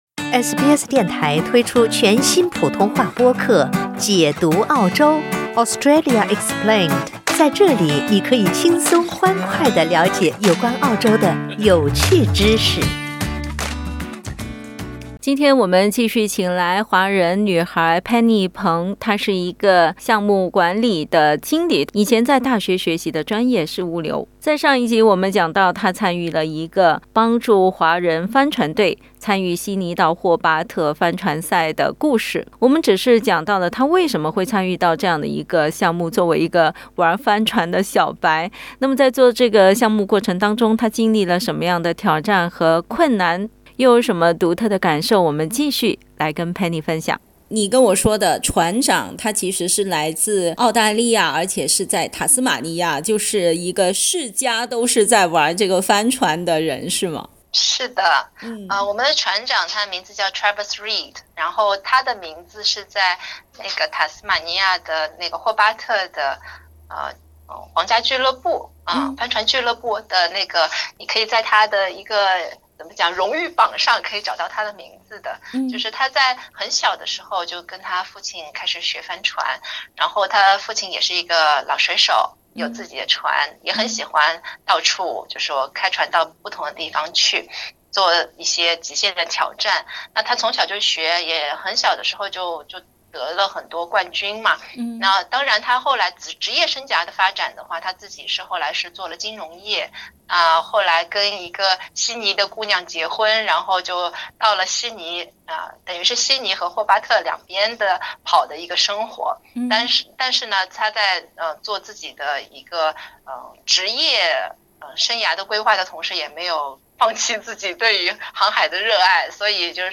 每天在解决问题的过程中，也了解了澳大利亚土生土长的帆船高手是如何炼成的。 (请听采访) 澳大利亚人必须与他人保持至少1.5米的社交距离，请查看您所在州或领地的最新社交限制措施。